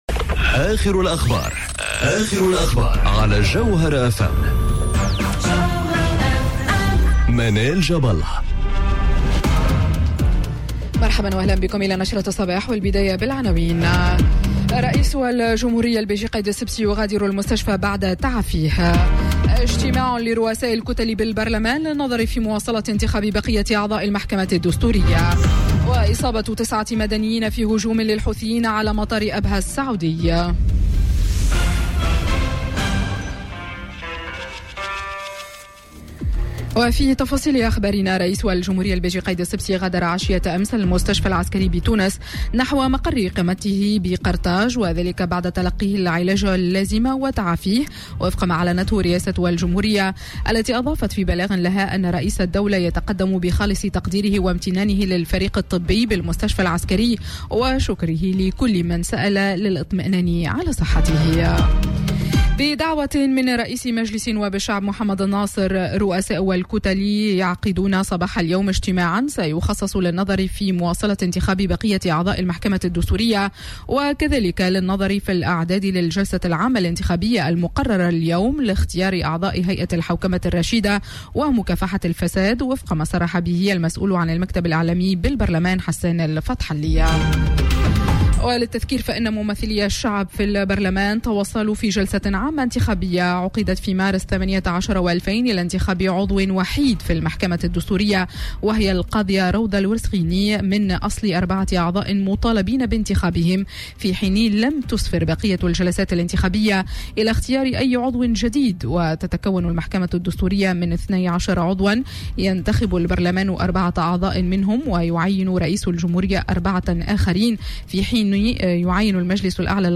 Journal Info 07h00 du mardi 02 juillet 2019